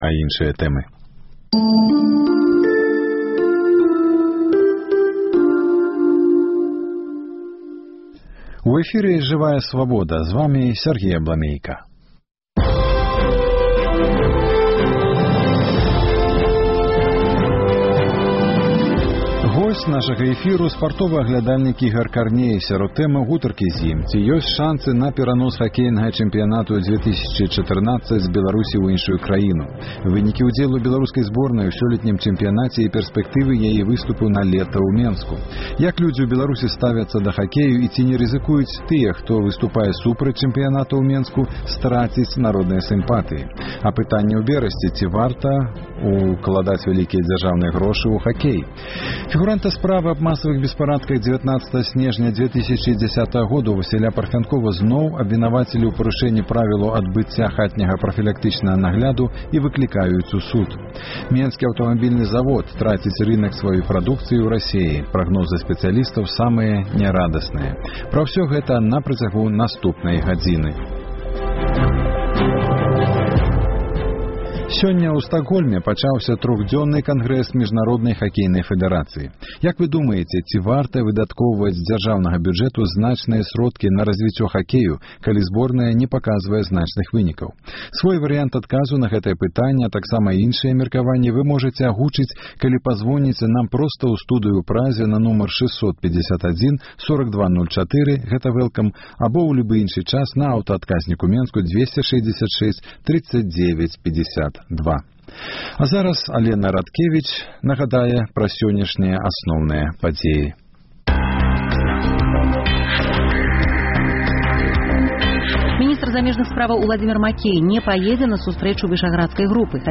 Апытаньне ў Берасьці. Ці варта ўкладаць вялікія дзяржаўныя грошы ў хакей?